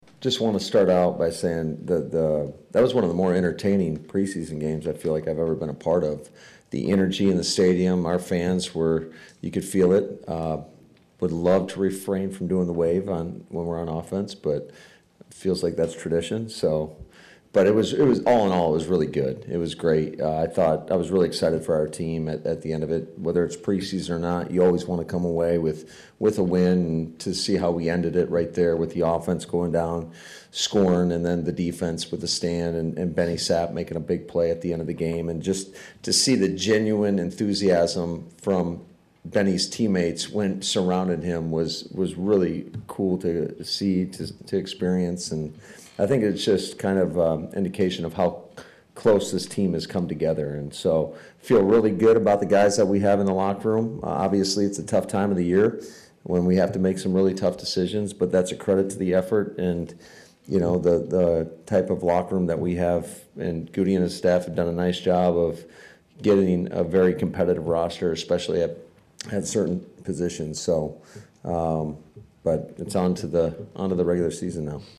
The Packers finished with two wins in three games and afterward, Head Coach Matt LaFleur was all smiles: